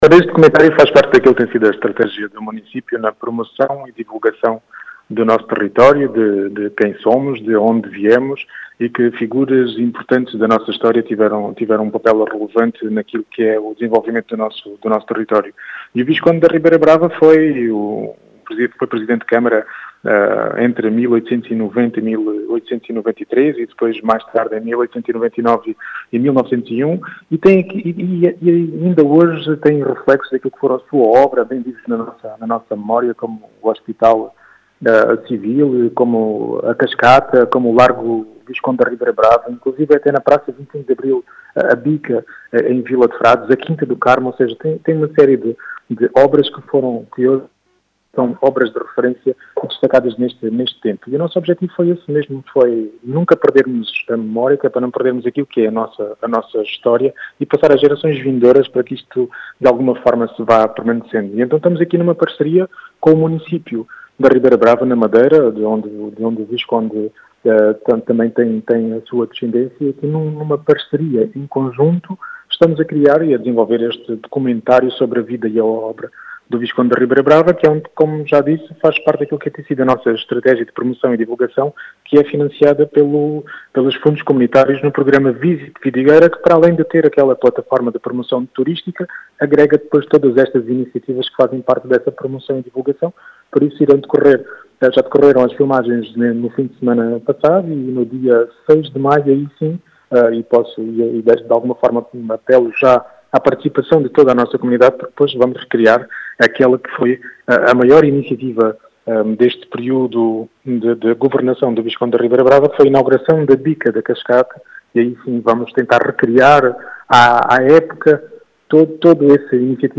As explicações são de Rui Raposo, presidente da Câmara Municipal de Vidigueira, que fala do papel “relevante” de Francisco de Herédia no concelho de Vidigueira.
Rui-Raposo.mp3